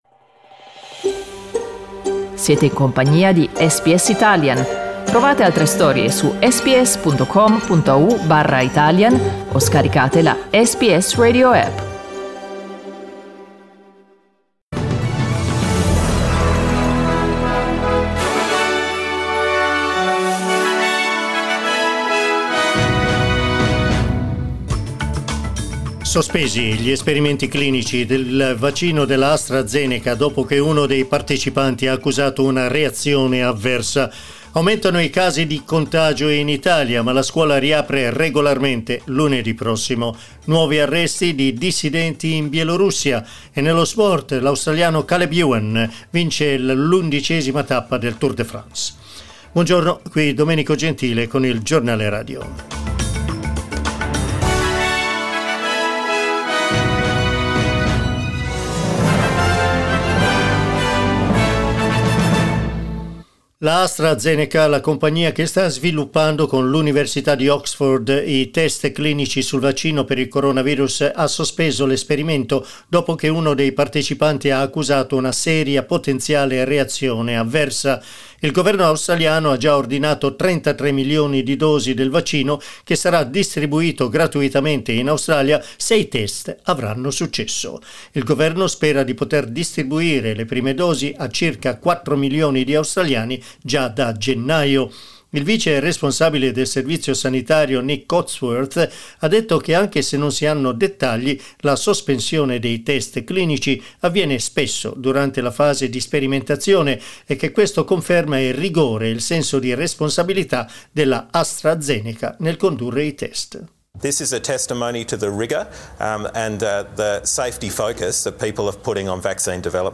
The Italian news bulletin that went to air this morning on SBS Radio.